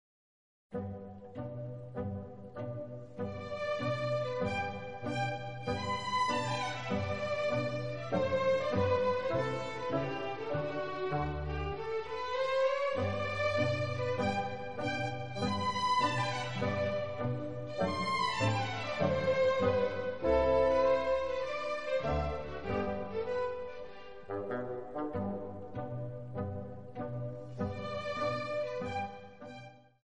Haydn - Symphony 101 "The Clock"